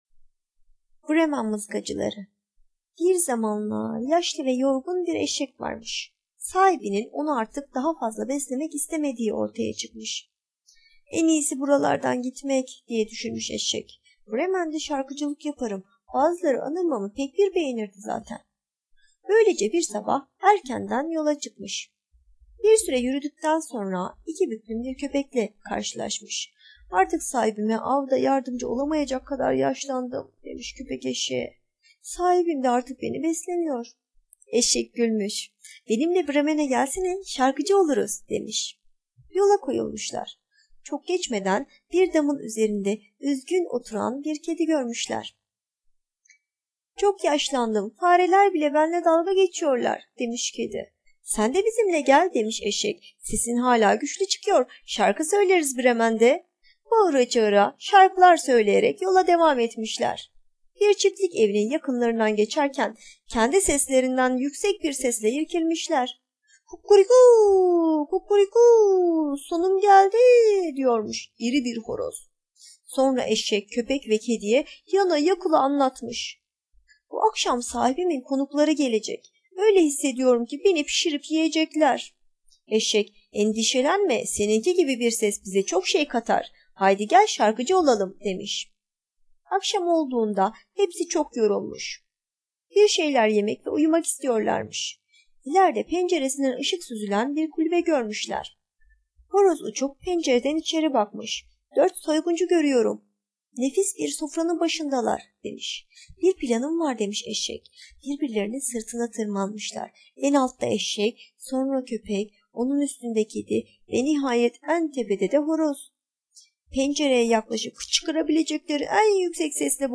Bremen Mızıkacıları sesli masalı, mp3 dinle indir
Sesli Çocuk Masalları